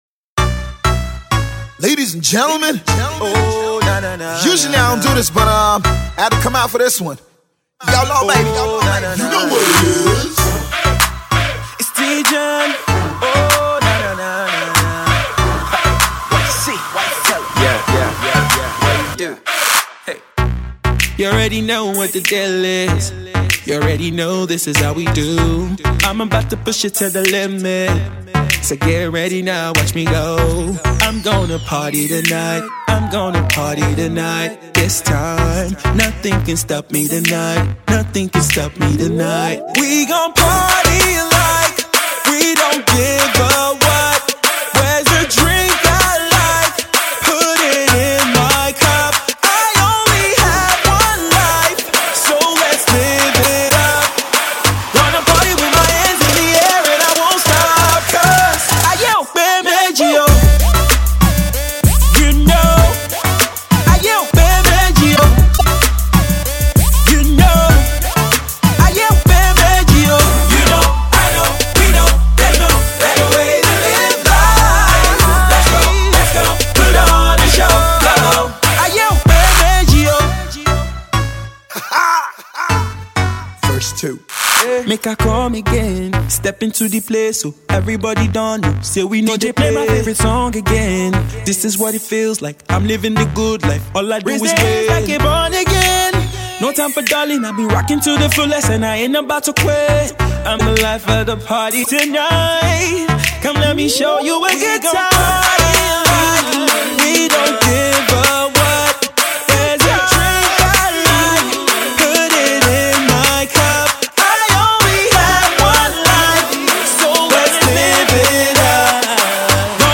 hip-hop/R nB fusion